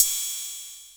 DB - Percussion (24).wav